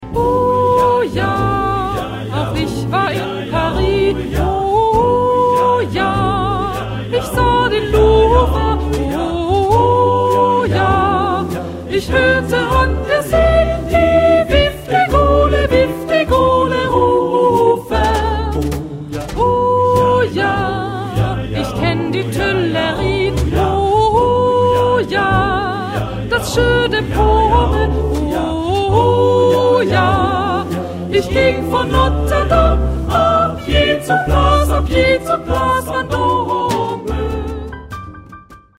SATB (4 voices mixed).
Choral jazz.
Blues ...
Mood of the piece: cantabile ; jazzy ; humorous
Tonality: G major